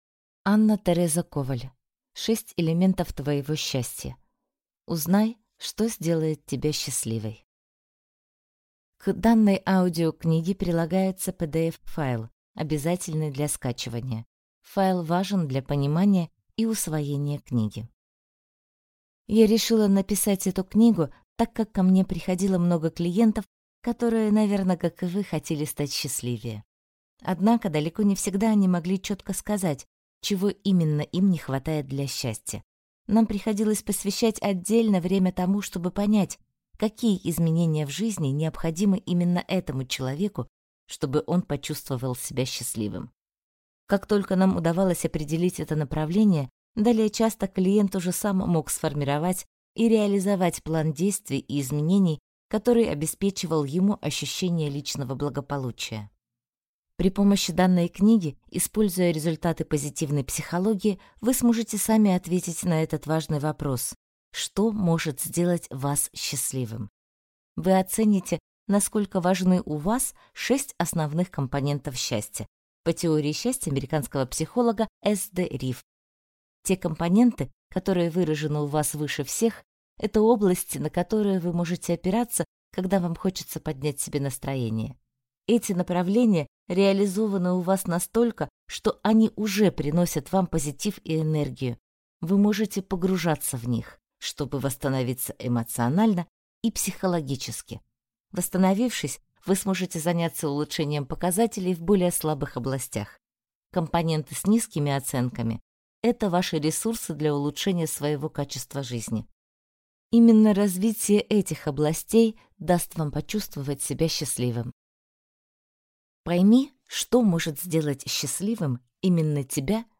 Аудиокнига 6 элементов твоего счастья.